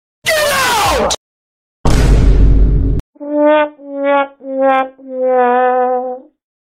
get out/vine boom/sad trombone Sound Button: Unblocked Meme Soundboard
Sound Effects